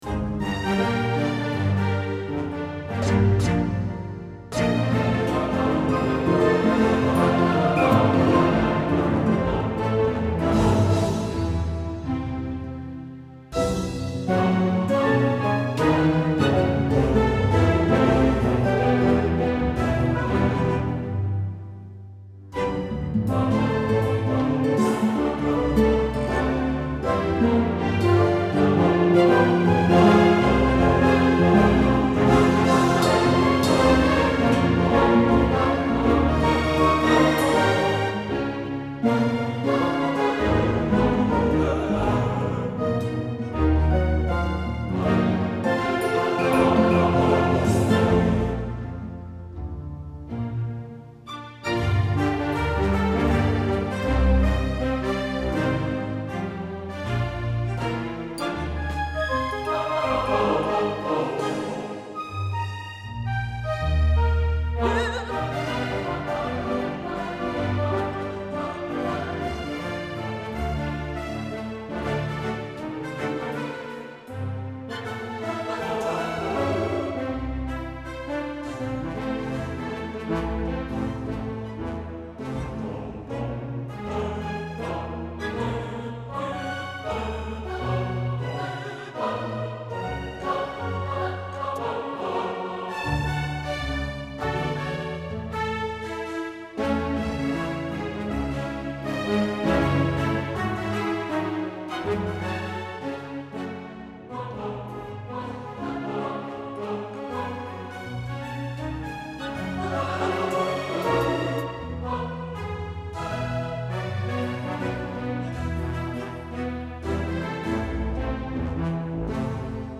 Orchestra & Combo